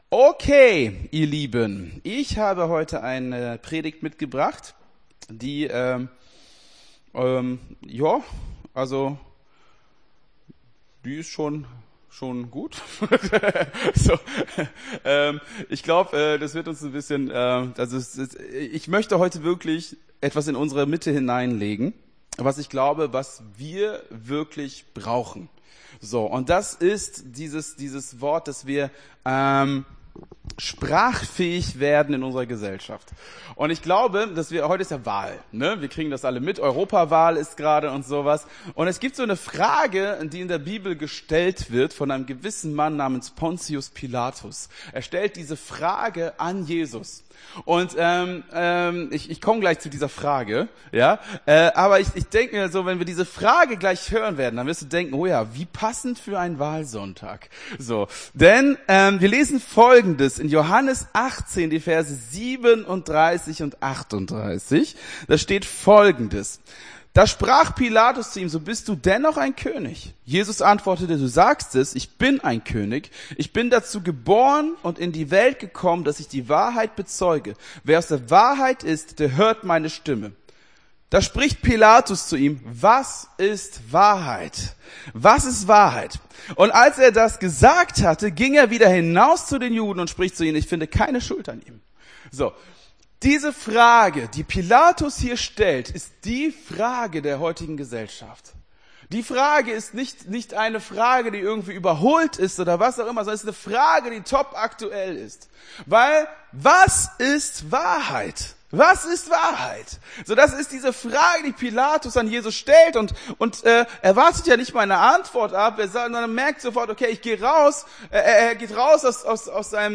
Gottesdienst 09.06.24 - FCG Hagen